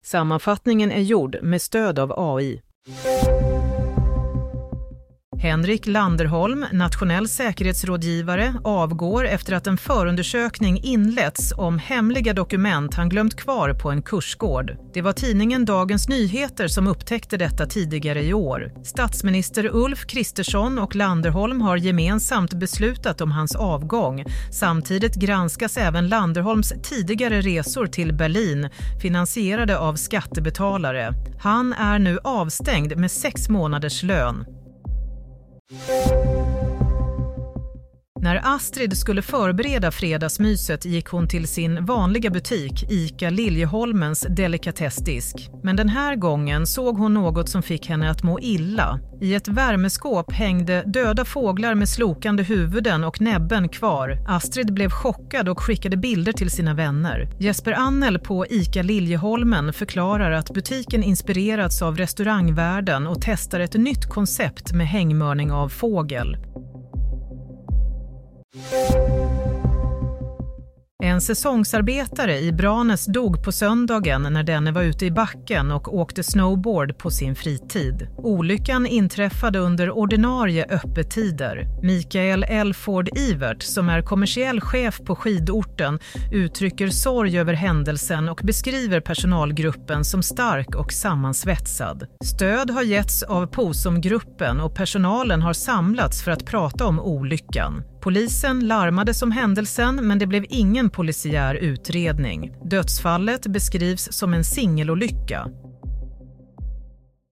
Nyhetssammanfattning - 27 januari 16.00